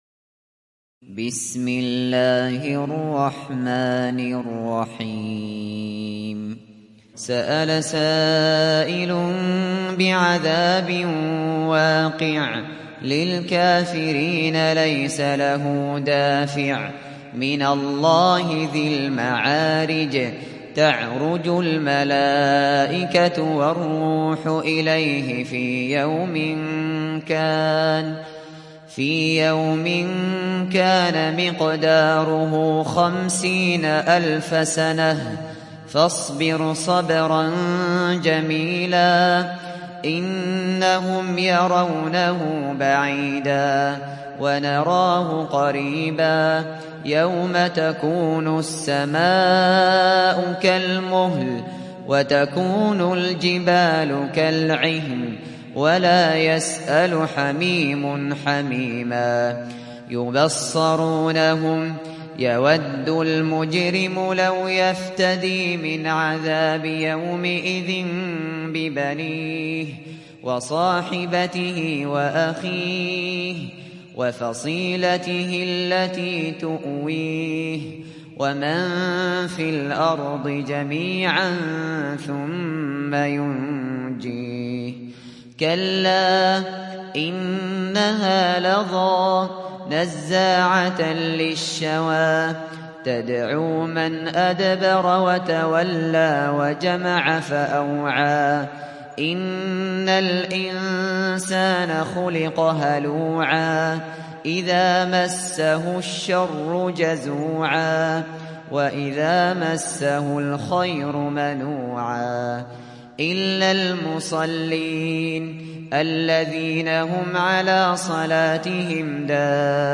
تحميل سورة المعارج mp3 بصوت أبو بكر الشاطري برواية حفص عن عاصم, تحميل استماع القرآن الكريم على الجوال mp3 كاملا بروابط مباشرة وسريعة